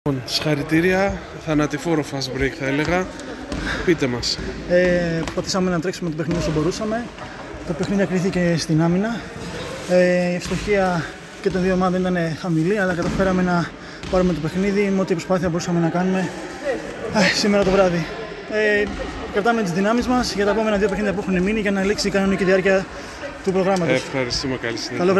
GAME INTERVIEWS:
Παίκτης ACTNET